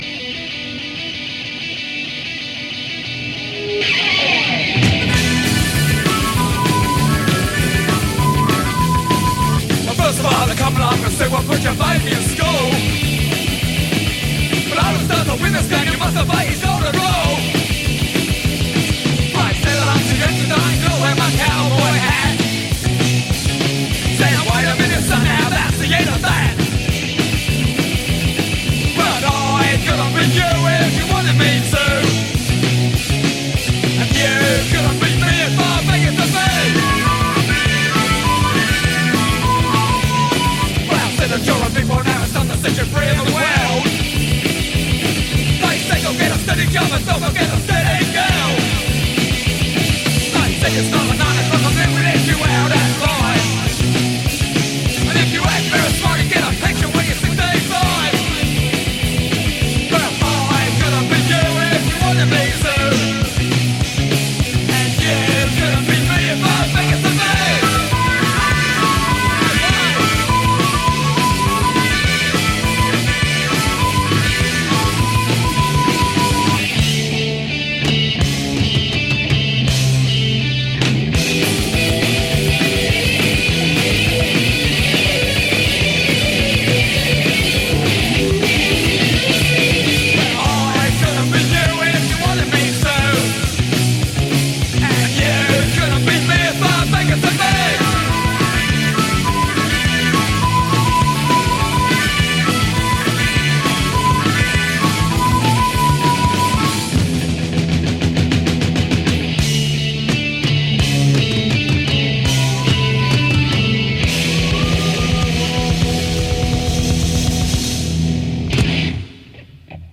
one of the earliest in the first wave of Punk from the UK.